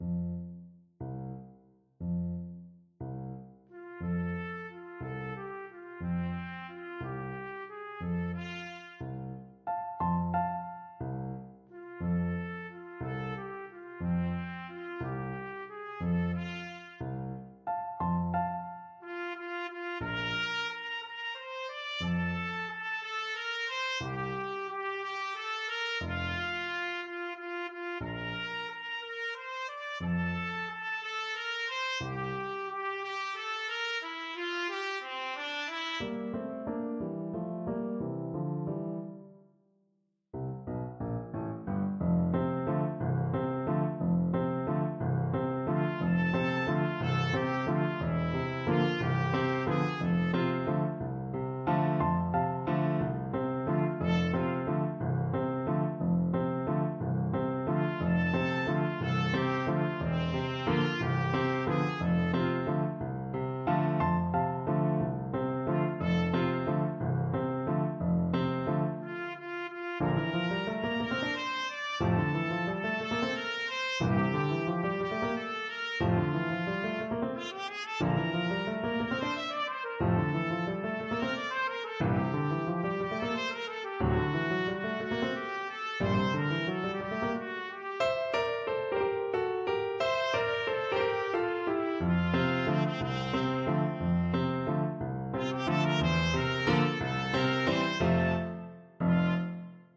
Trumpet 1Trumpet 2
Pochissimo pi mosso = 144 . =60
6/8 (View more 6/8 Music)
Classical (View more Classical Trumpet Duet Music)